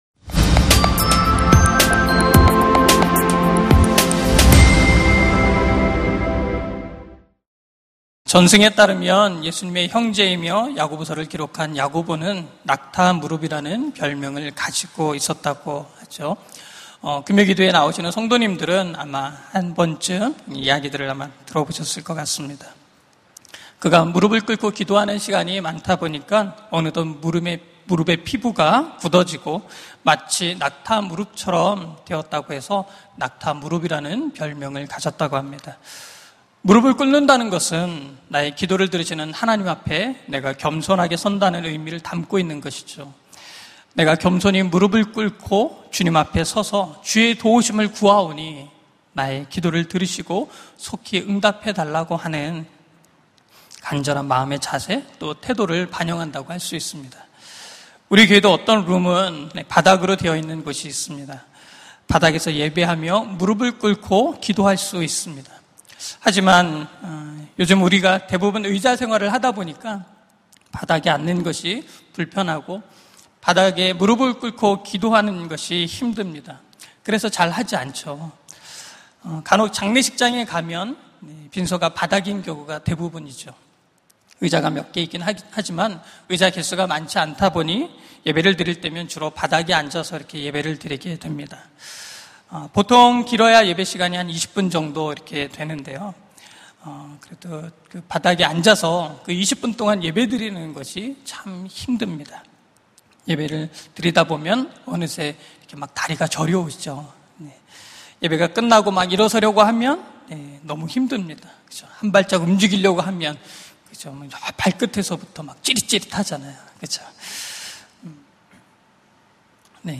설교 : 금요심야기도회 (분당채플) 하나님께 올려드리는 기도!